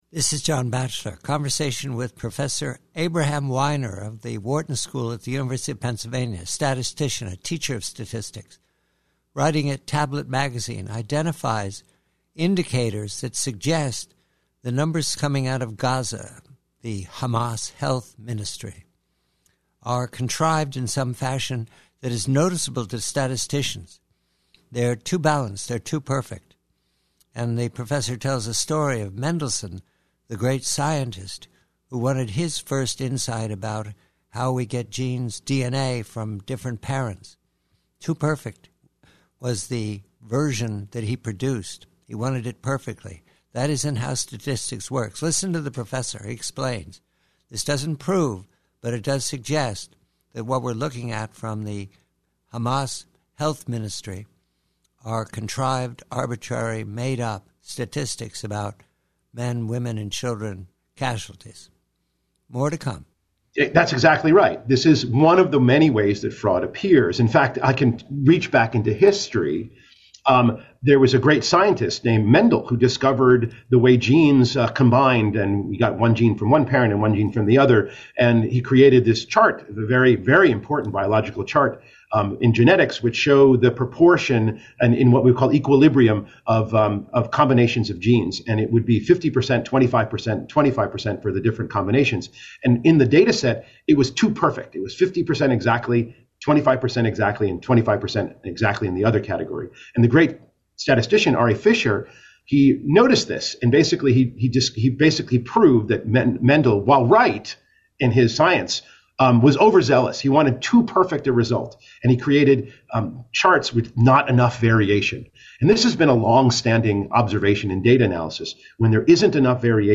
Excerpt from a conversation